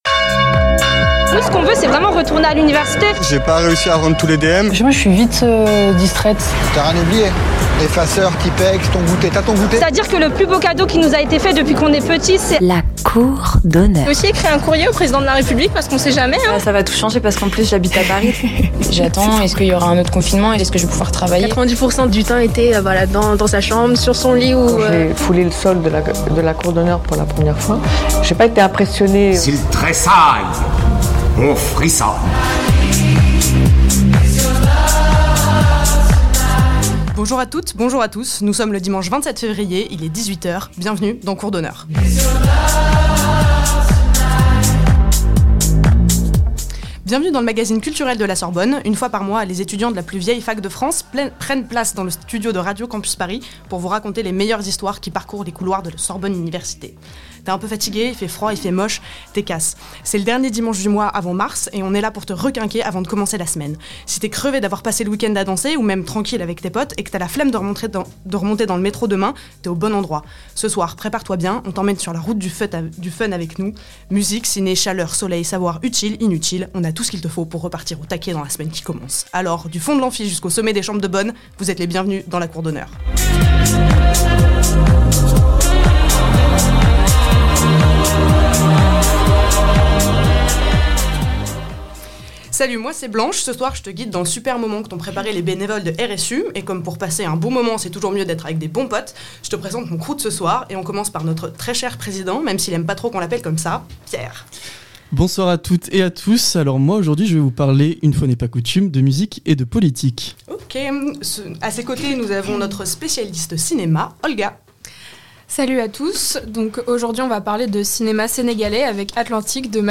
Une fois par mois, les étudiants de la plus vieille fac de France viennent squatter les locaux de RCP pour vous raconter les meilleurs histoires de notre université, les sorties ciné du quartier latin, le parcours d'anciens, les anecdotes sur la Sorbonne mère...